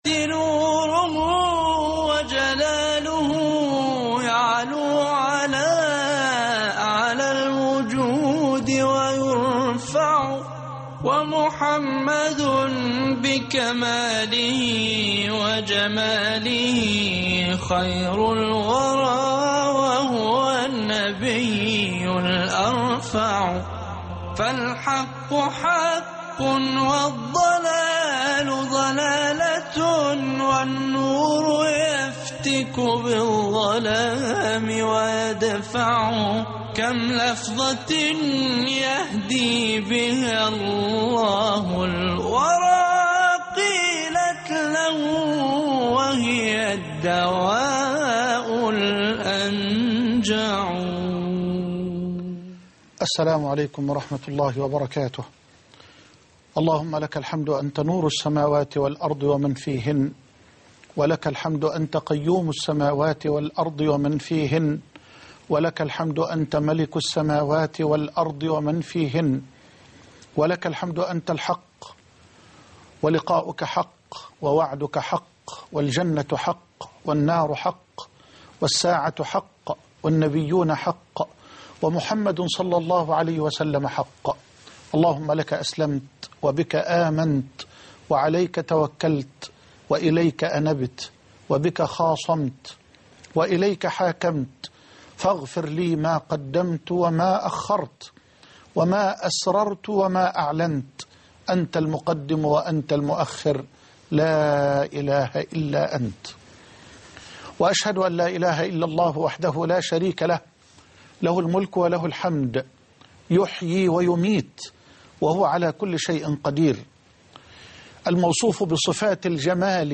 الدعاء (31/8/2010) كلمة - فضيلة الشيخ محمد حسان